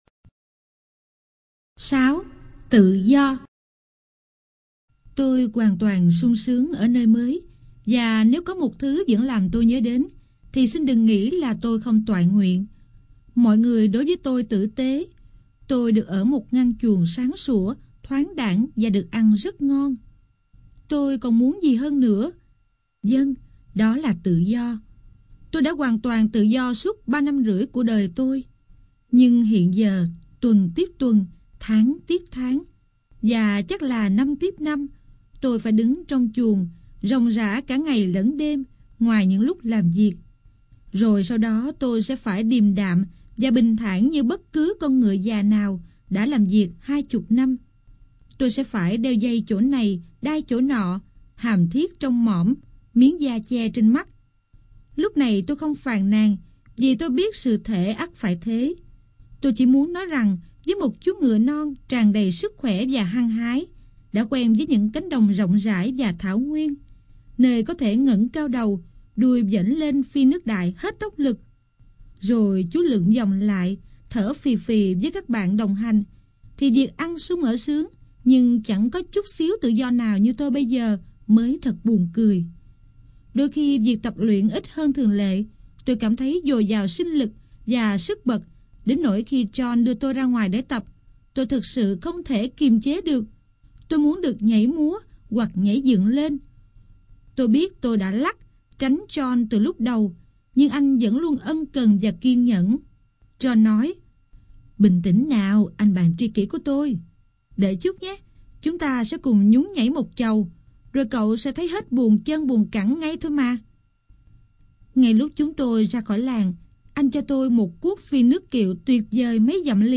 Sách nói Ngựa Ô Yêu Dấu - Anna Sewell - Sách Nói Online Hay